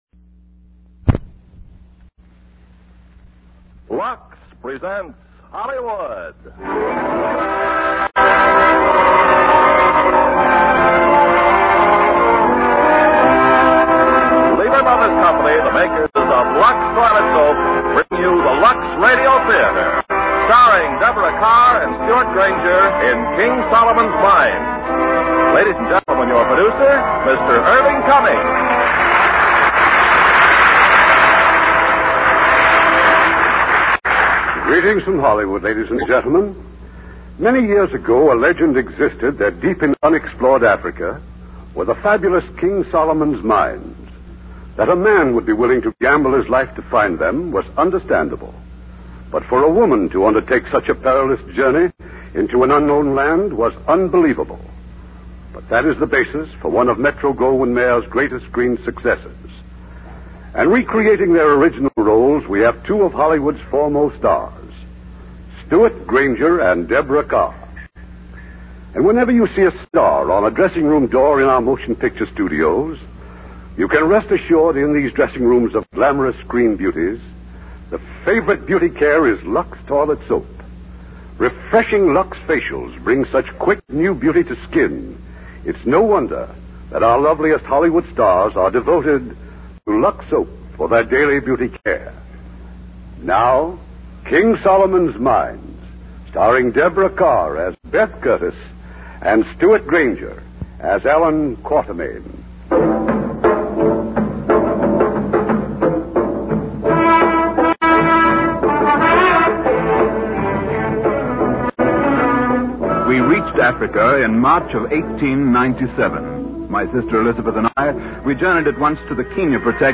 Lux Radio Theater Radio Show
King Solomon's Mines, starring Deborah Kerr, Stewart Granger